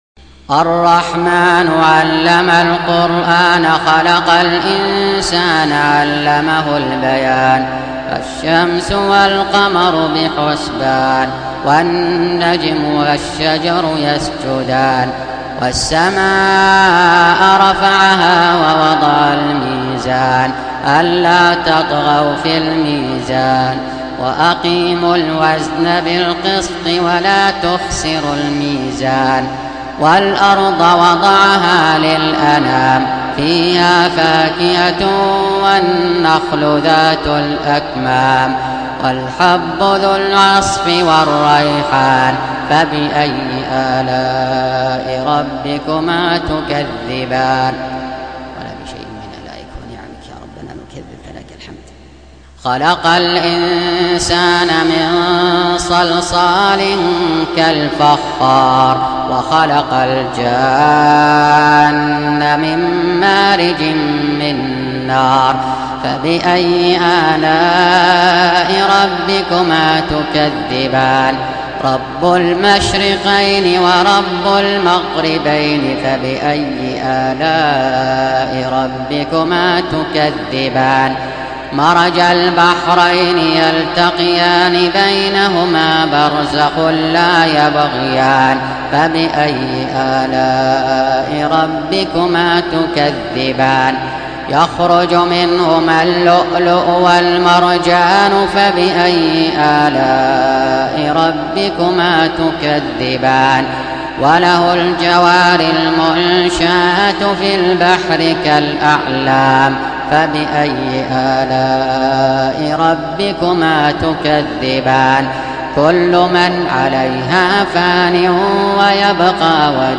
Surah Repeating تكرار السورة Download Surah حمّل السورة Reciting Murattalah Audio for 55. Surah Ar-Rahm�n سورة الرحمن N.B *Surah Includes Al-Basmalah Reciters Sequents تتابع التلاوات Reciters Repeats تكرار التلاوات